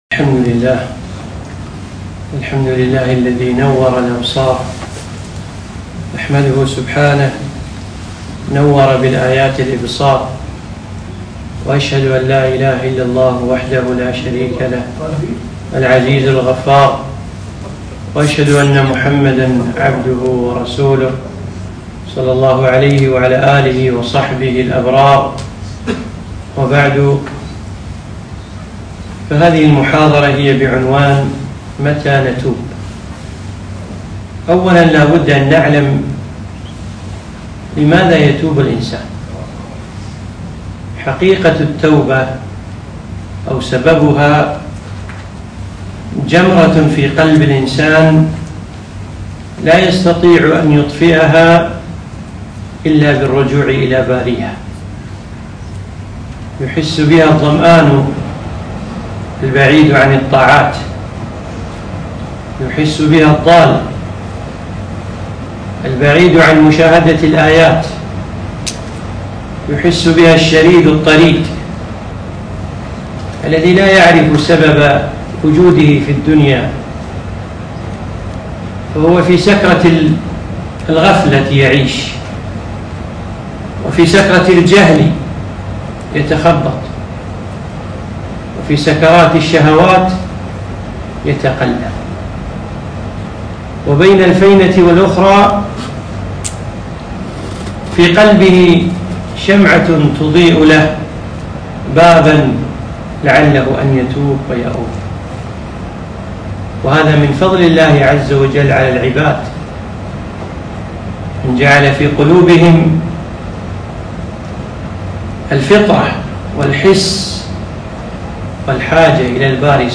محاضرة قيمة - متى تتوب ؟